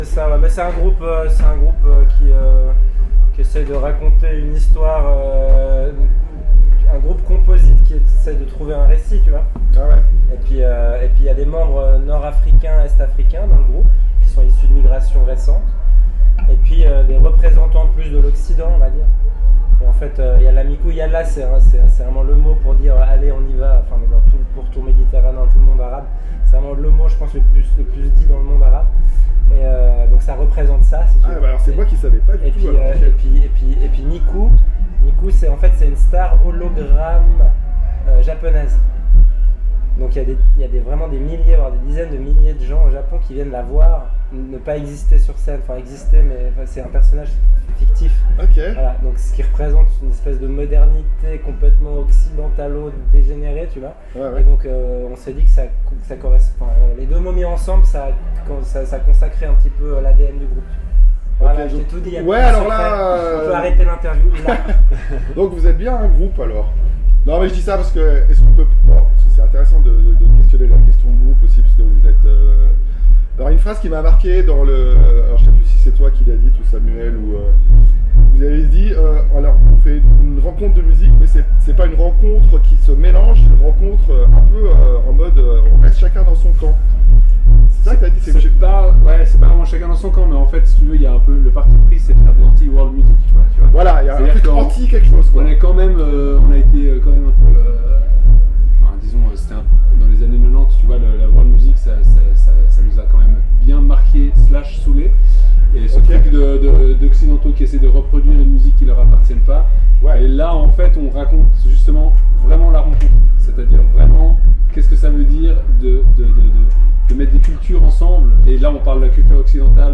Yalla Miku, collectif undertown de la Genève internationale des squats, mélange des cultures et de leur musique, une anti world musique en écho a l'authenticité du punk, plusieurs décenies plus tard. Interview enregistré en sortie de concert au Paléo festival de nyon, 23 Juillet 2024, club tent.